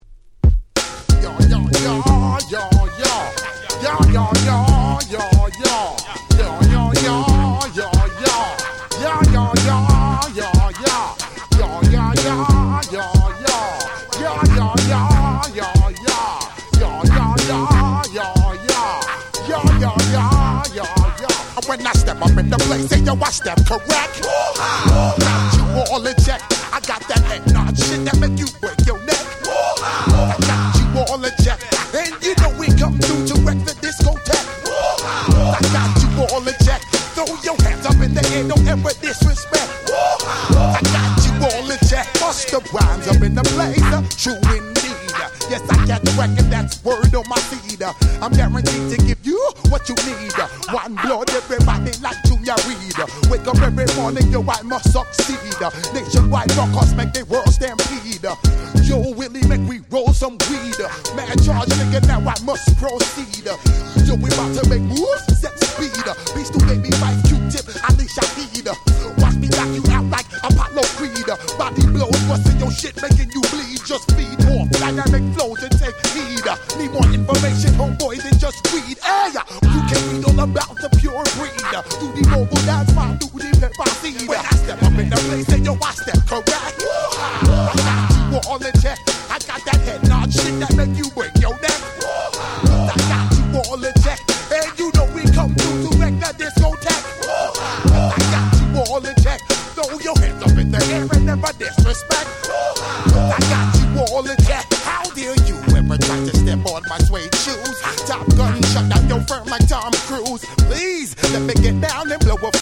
96’ Super Hit Hip Hop !!
90's Boom Bap ブーンバップ